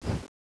Index of /server/sound/weapons/tfa_cso/dreadnova
slash_end.wav